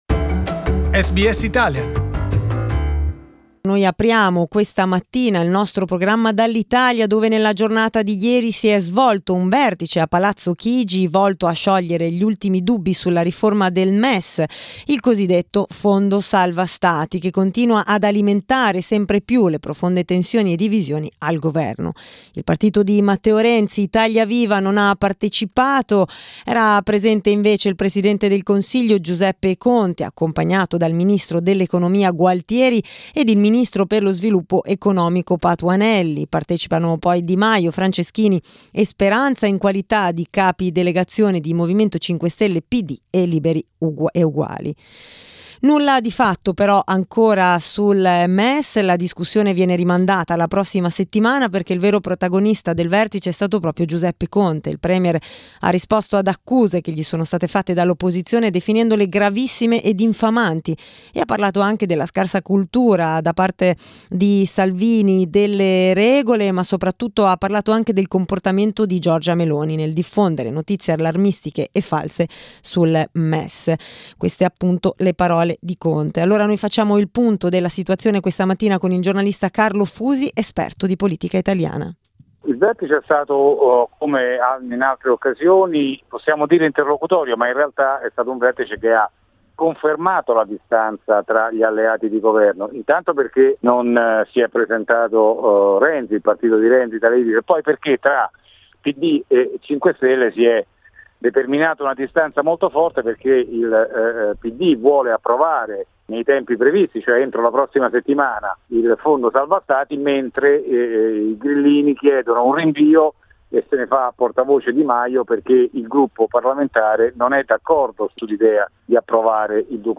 We talked about Italian politics with journalist